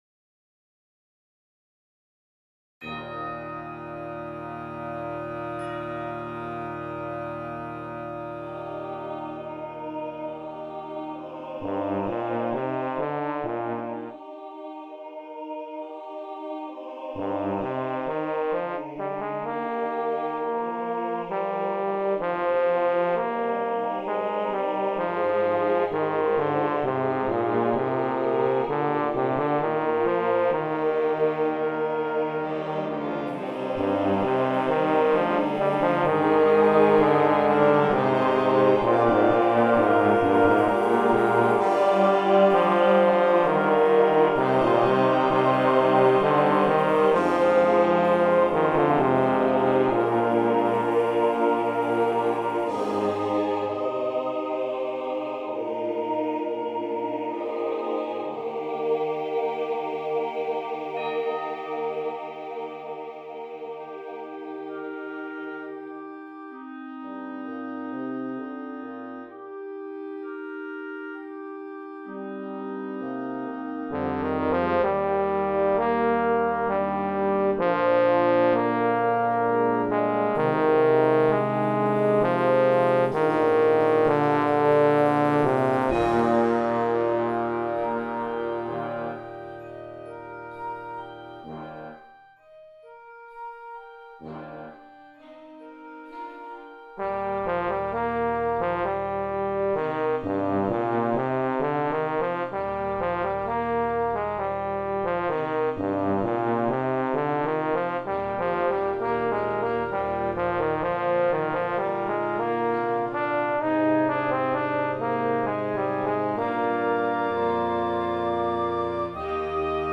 Concerto for Bass Trombone, Symphonic Band & Choir